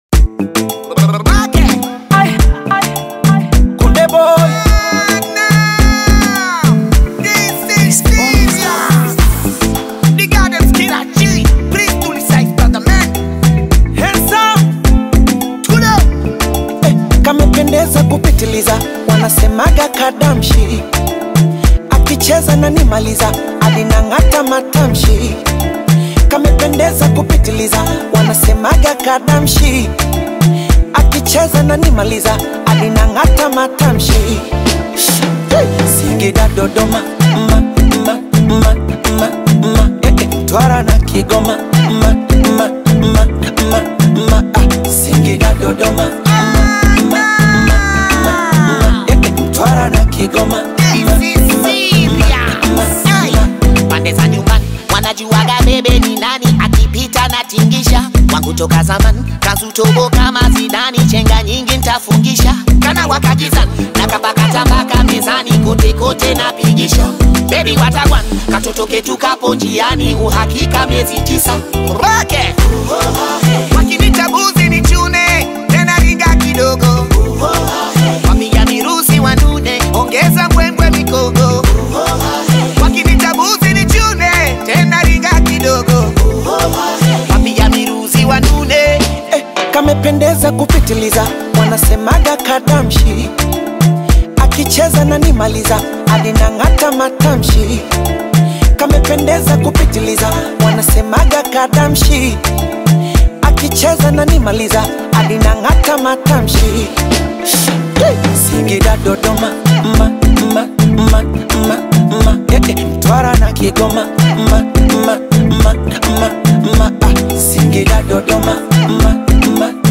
🎶 Genre: Bongo Flava